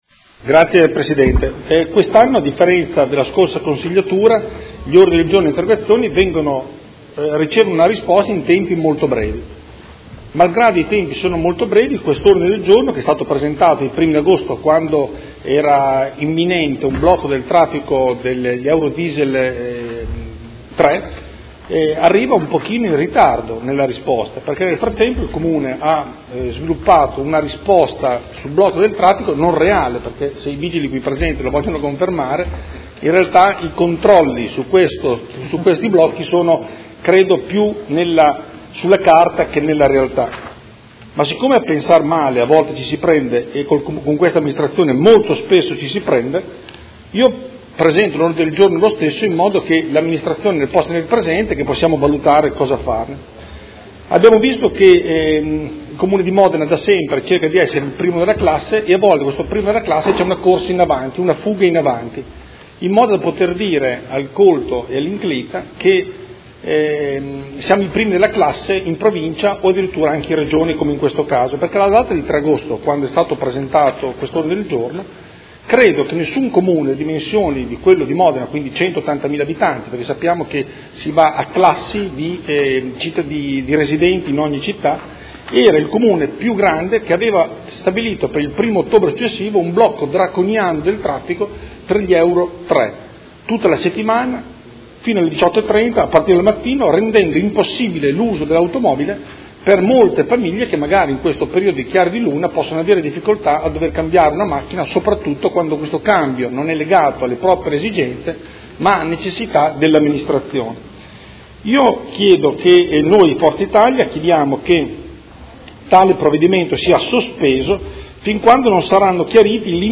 Seduta del 19/11/2015. Ordine del Giorno presentato dal Consigliere Galli (F.I.) avente per oggetto: Sospendere il blocco delle auto diesel euro 3
Audio Consiglio Comunale